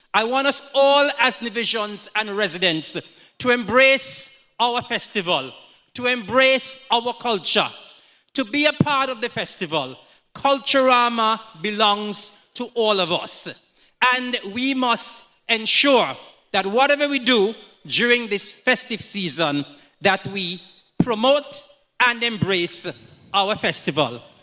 Dubbed the greatest summer lime, a ceremony to mark the occasion was held on Thursday, July 24th at the NEDACS Cultural Complex.
The ceremony began with a Prayer, followed by the National Anthem, brief remarks by the Minister of Culture, Hon. Eric Evelyn, who stated that the festival belongs to us: